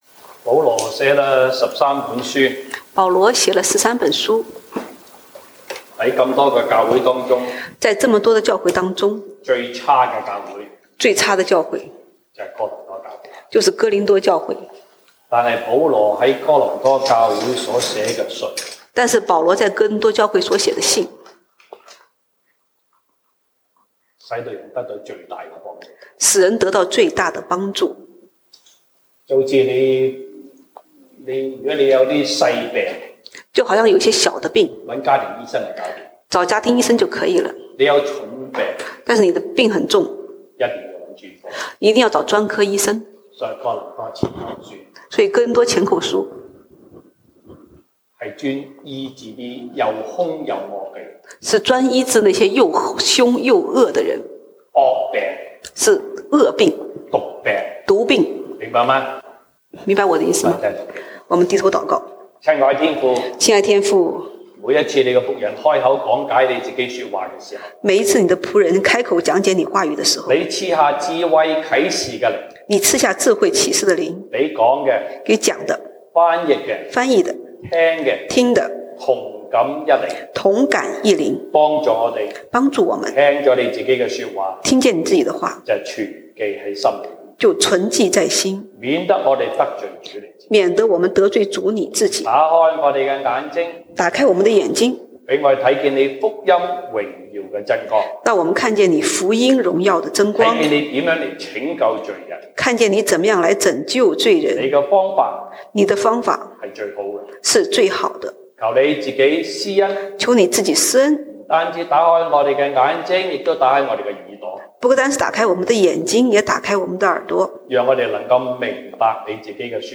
西堂證道(粵語/國語) Sunday Service Chinese: 這就是神如何拯救罪人
Passage: 歌林多前書 1 Corinthians 5:1-5 Service Type: 西堂證道(粵語/國語) Sunday Service Chinese Topics: 這就是神如何拯救罪人